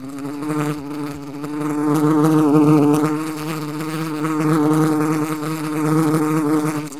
flies2.wav